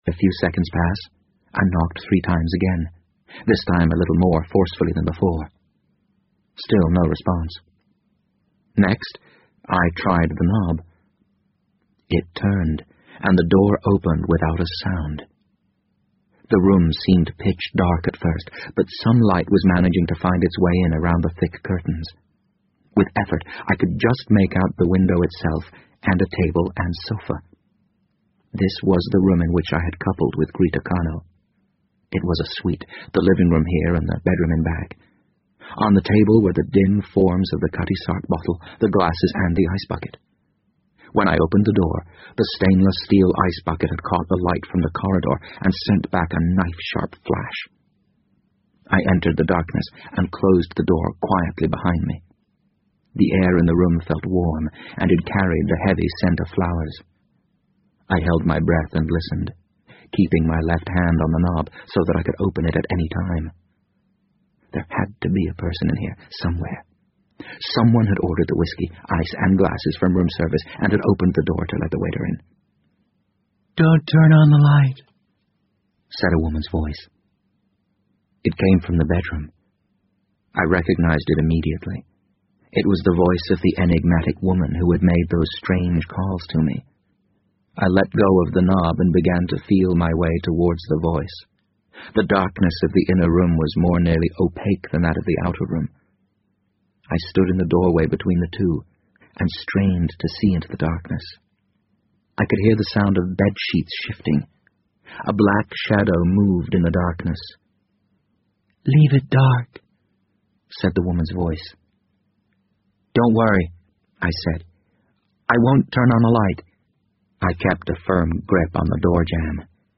BBC英文广播剧在线听 The Wind Up Bird 006 - 21 听力文件下载—在线英语听力室